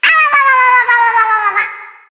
One of Yoshi's voice clips in Mario Kart Wii